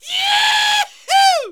JAUCHZER   1.wav